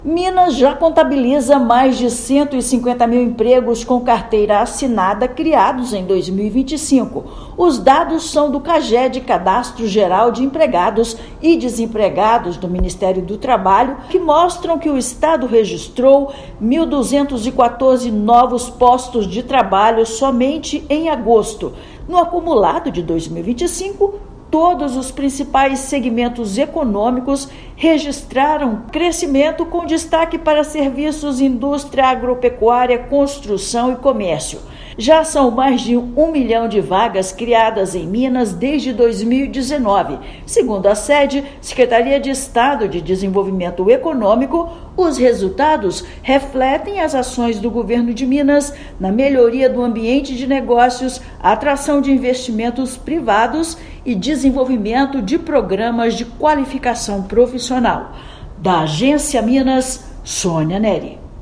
Estado gerou 1.214 vagas em agosto e mantém posição de destaque entre os maiores geradores de vagas do Brasil em 2025. Ouça matéria de rádio.